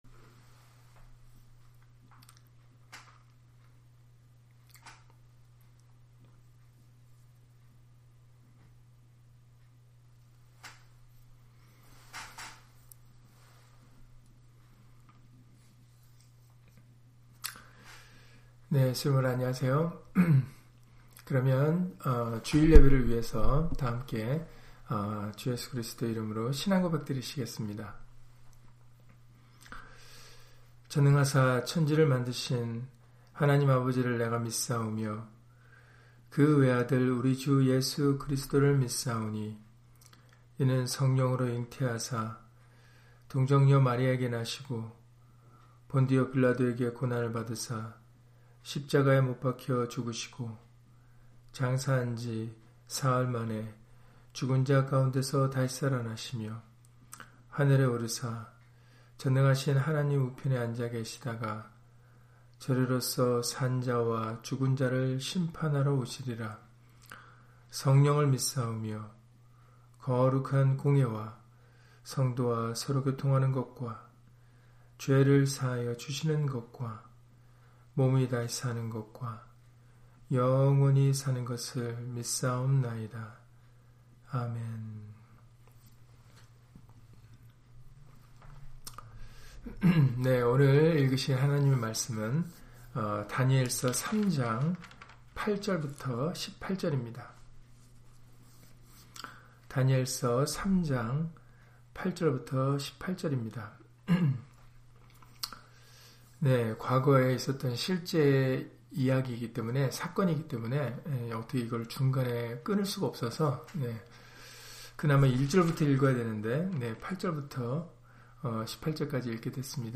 다니엘 3장 8-18절 [금으로 신상을 만든 느부갓네살왕] - 주일/수요예배 설교 - 주 예수 그리스도 이름 예배당